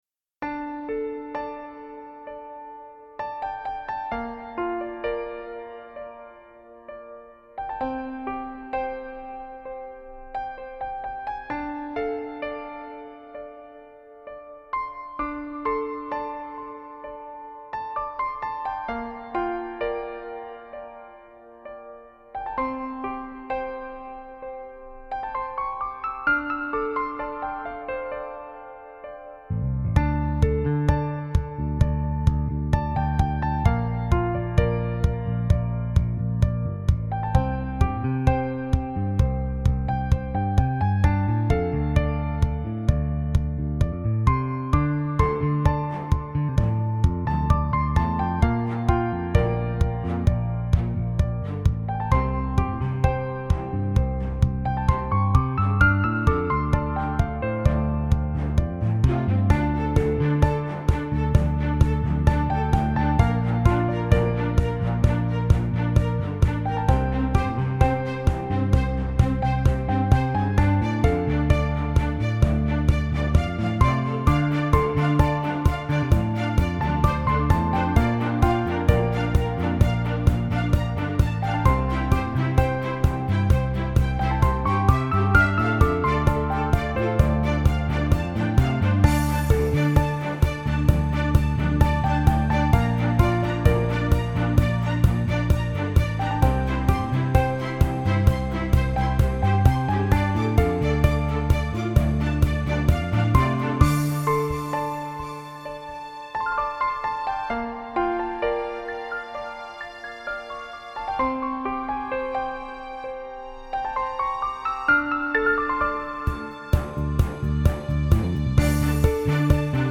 Anyway, the track itself is quite a simple piece: I did, in fact, try to make it simple. It’s somewhat influenced by Ravel’s Bolero and the Newsboys’ The Orphan, in that it is constantly building by both adding and removing layers. It’s also, perhaps, influenced a bit by some of Coldplay’s stuff: I was listening to Viva La Vida and Prospekt’s March recently, and I was interested to note that in lots of those tracks there’s the same ‘kick on every beat’-type thing going on.